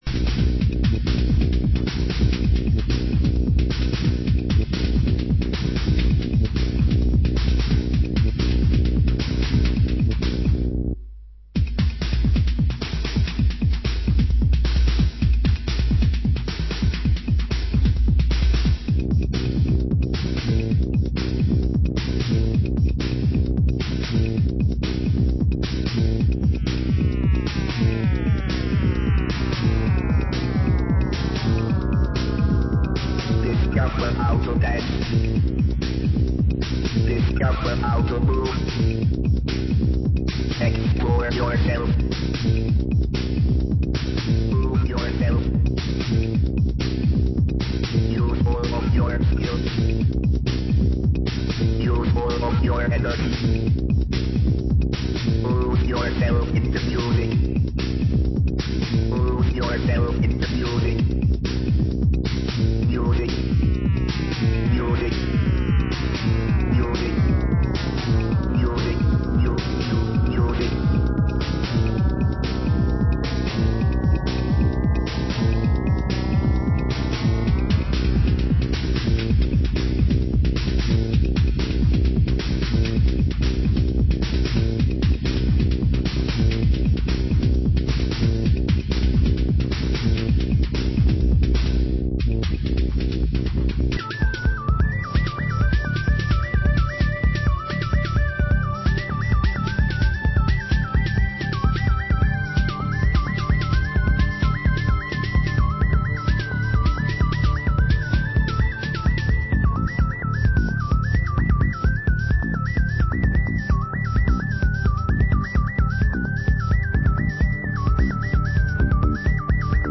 Genre: Detroit Techno